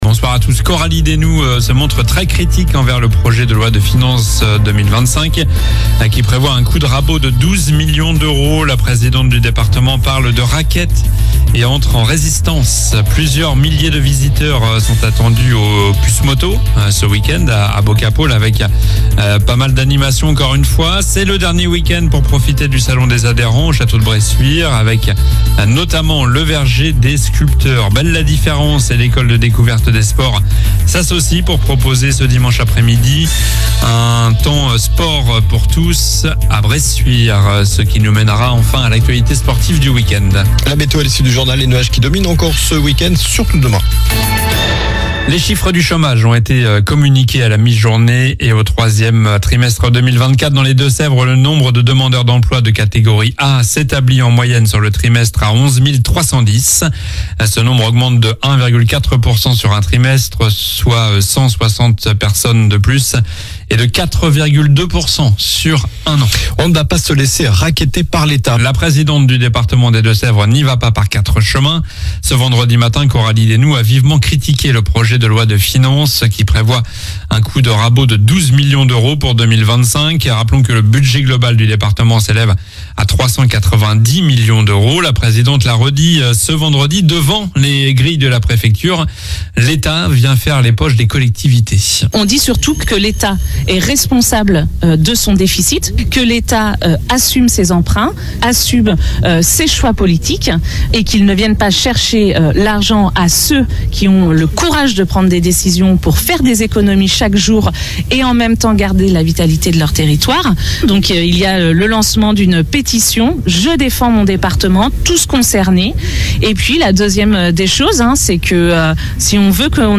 Journal du vendredi 25 octobre (soir)